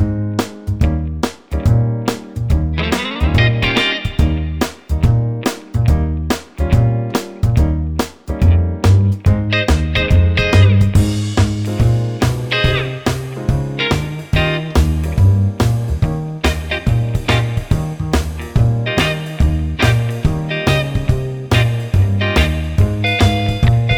No Sax Or Backing Vocals Pop (1960s) 2:06 Buy £1.50